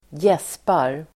Ladda ner uttalet
Uttal: [²j'es:par]